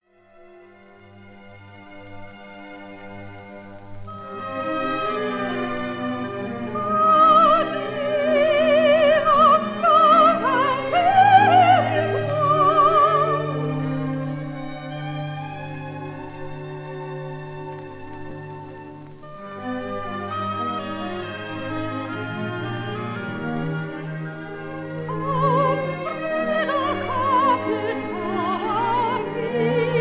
operatic arias
English soprano